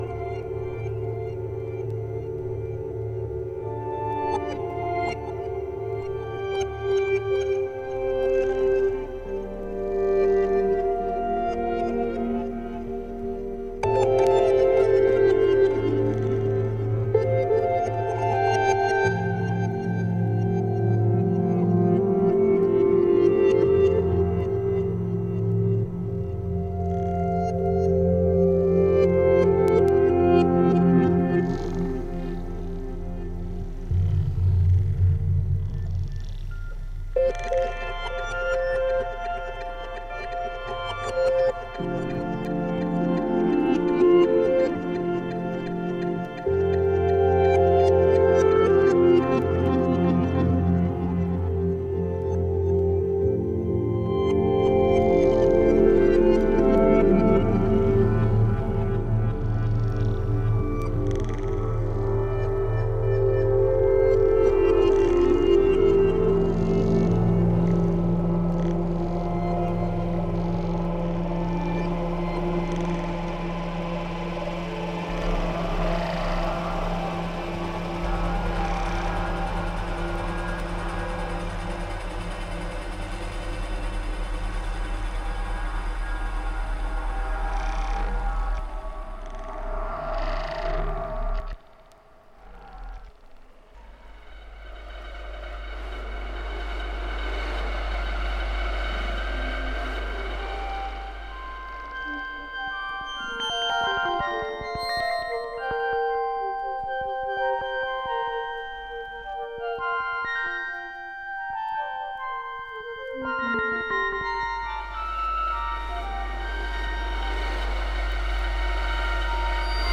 Só ficção científica.